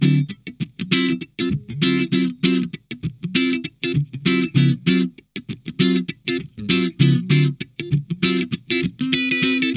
DEEP CHUG 3.wav